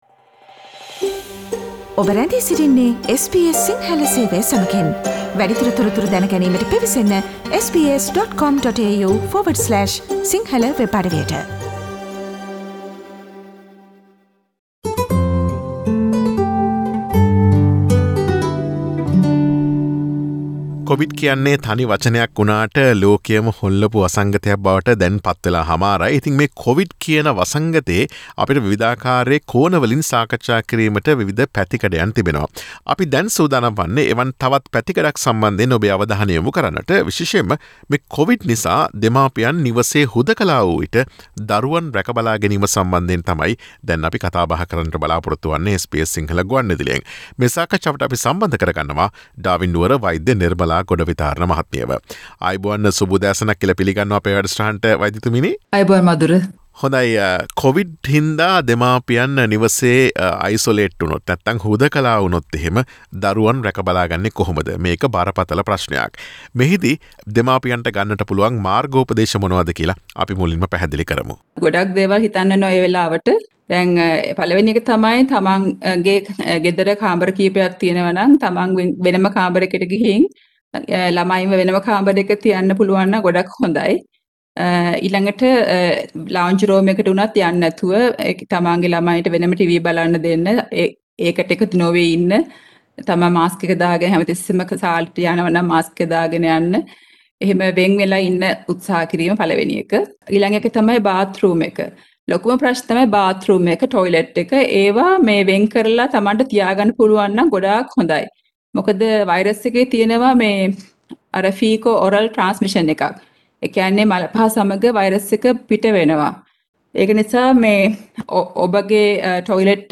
කොවිඩ් නිසා දෙමාපියන් නිවසේ හුදකලා වූ විට දරුවන් රැකබලා ගැනීමේ මාර්ගෝපදේශ පිළිබඳ දැනගැනීමට SBS සිංහල ගුවන් විදුලිය සිදුකළ මෙම සාකච්ඡාවට සවන් දෙන්න.